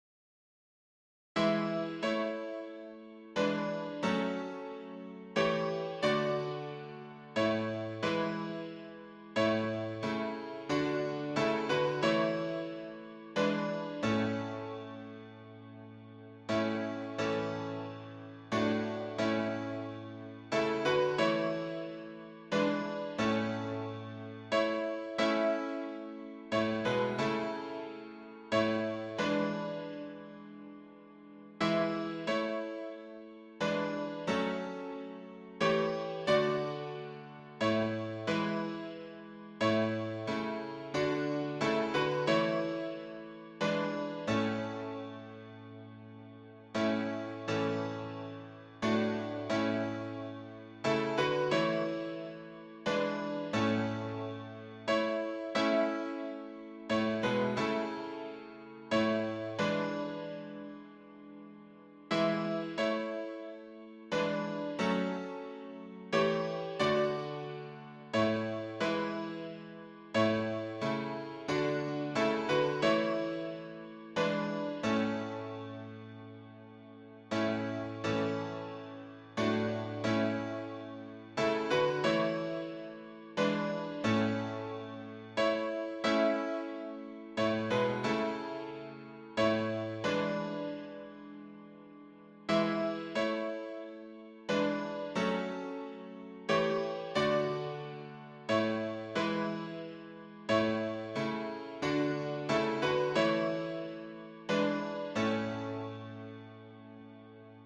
示唱